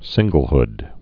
(sĭnggəl-hd)